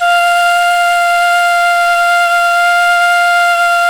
NYE FLUTE04L.wav